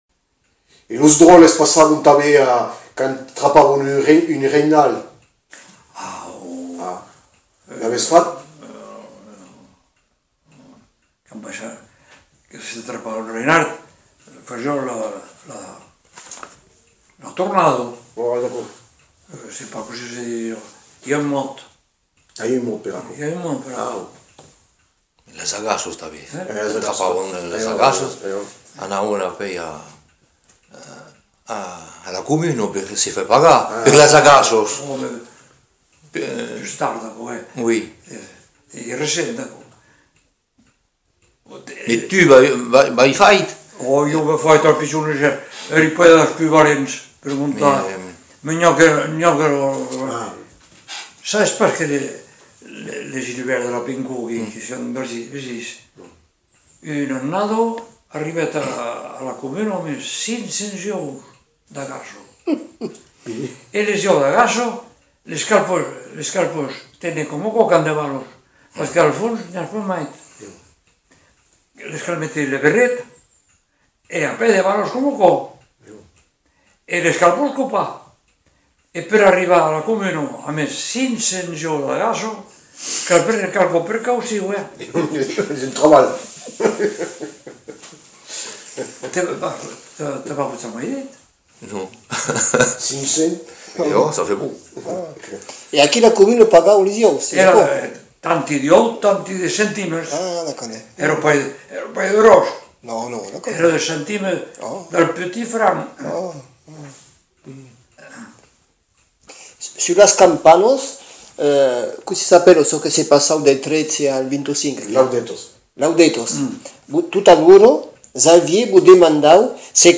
Aire culturelle : Lauragais
Lieu : Le Faget
Genre : témoignage thématique